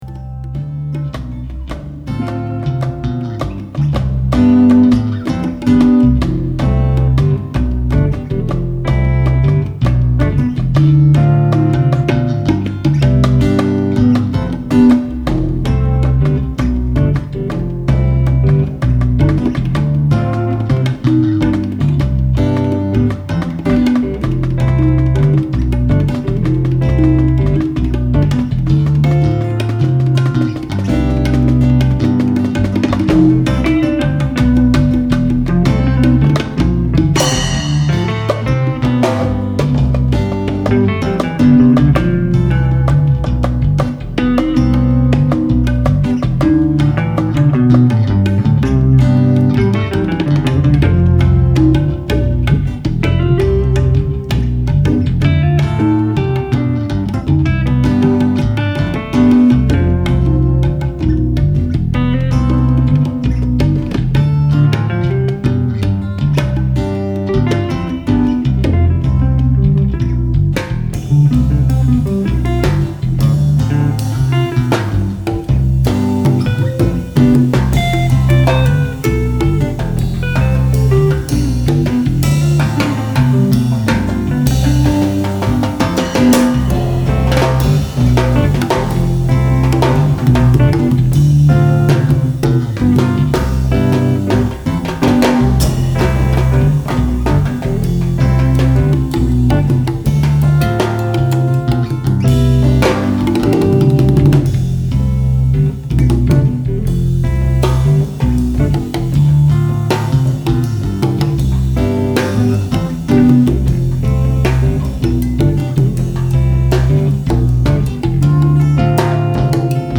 Nimbus2_(Live Trio LIiving room on mic)